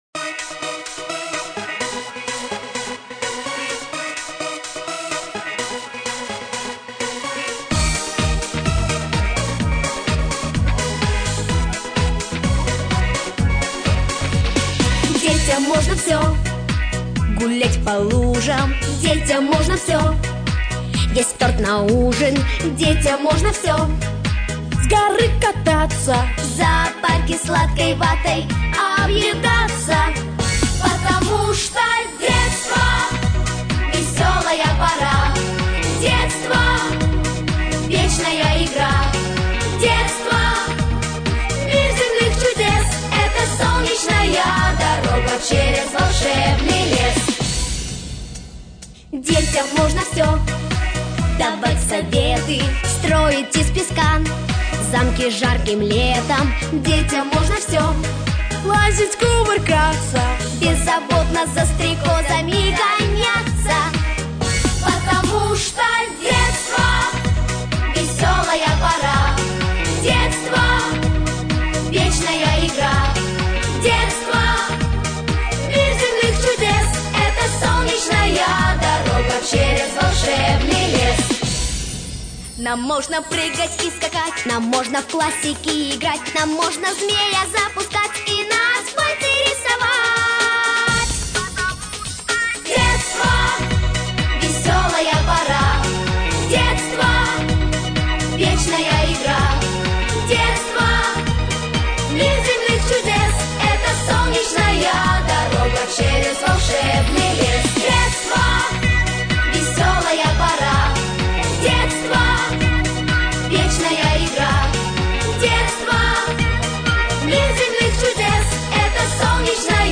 Детские песни / Песни про Лето скачать